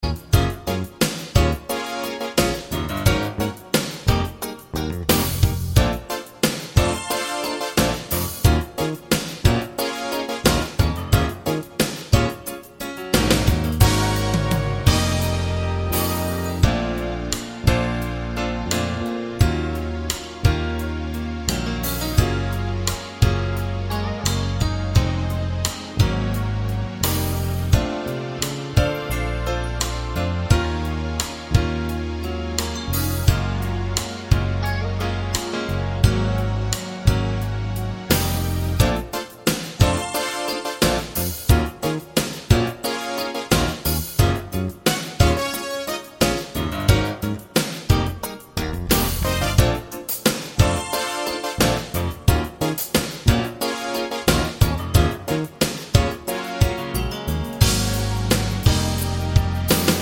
no Backing Vocals R'n'B / Hip Hop 4:16 Buy £1.50